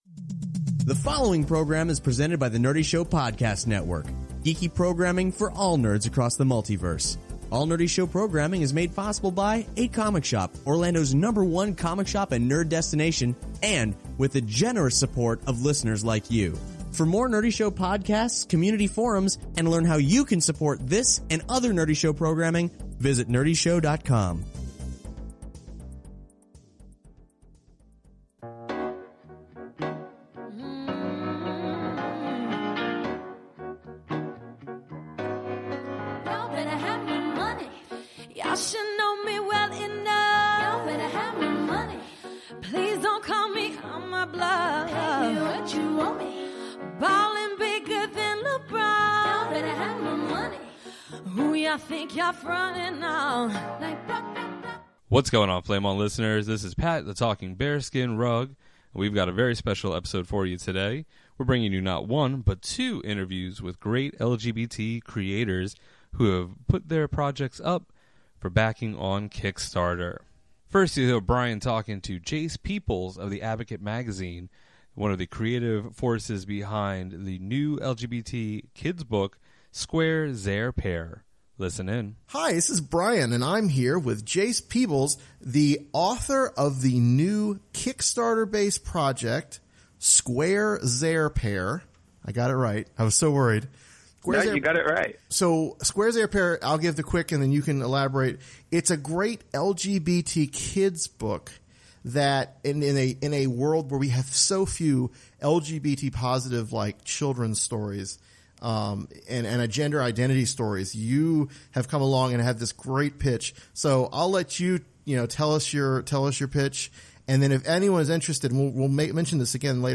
Every now and then, we love to feature Kickstarter projects with LGBT themes and interview the creators behind them.